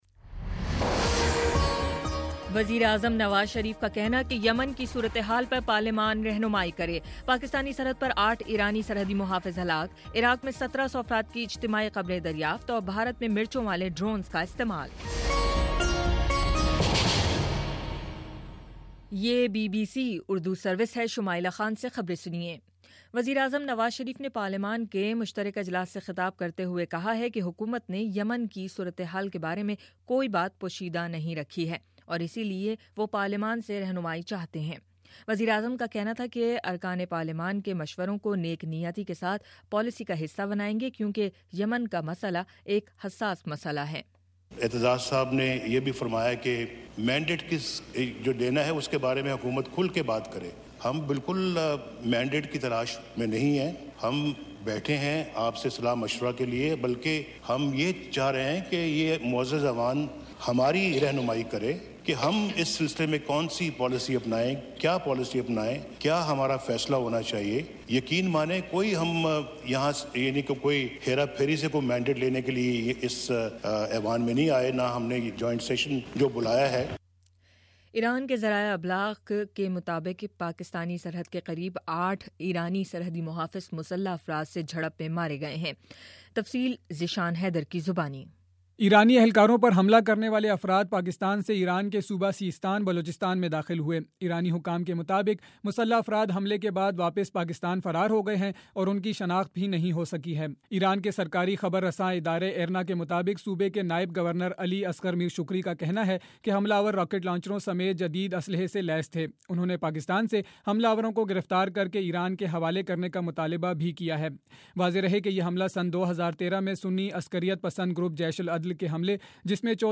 اپریل 7 : شام پانچ بجے کا نیوز بُلیٹن